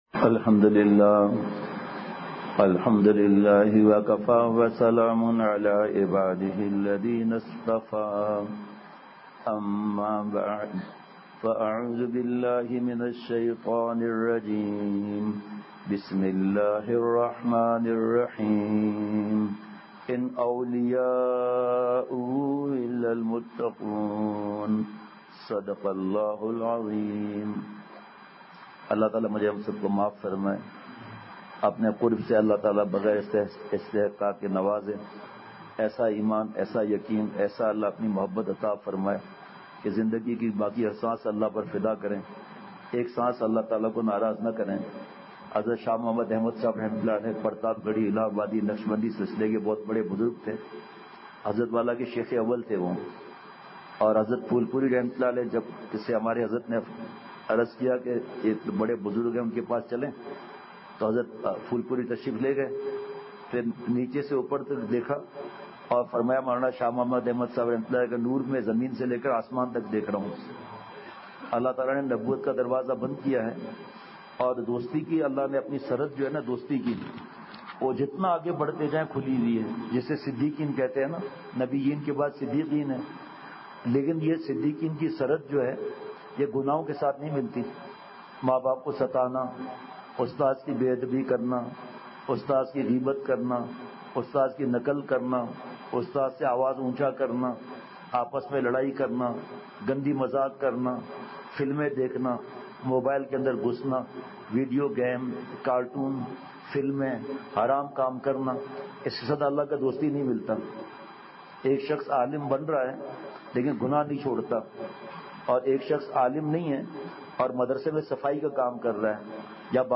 اصلاحی مجلس
بمقام:جامعہ اسلامیہ مفتاح العلوم فیض آباد پشین